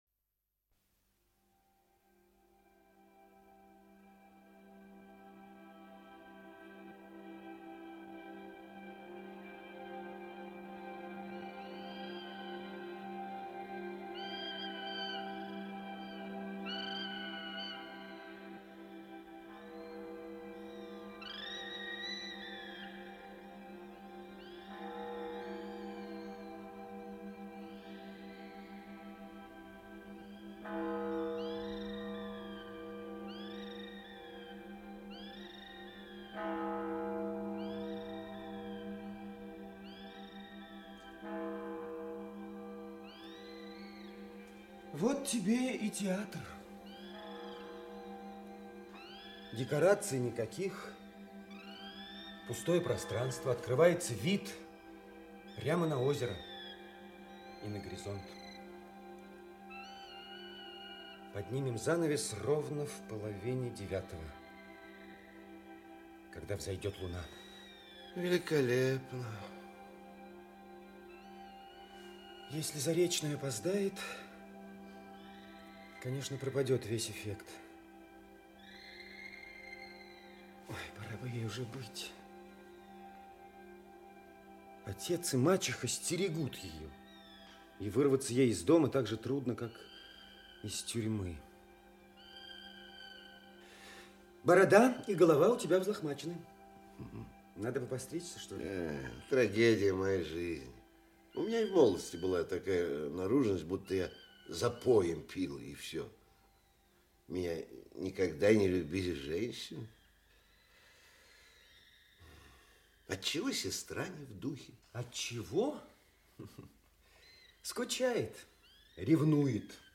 Действия 1 и 2 Автор Антон Чехов Читает аудиокнигу Актерский коллектив.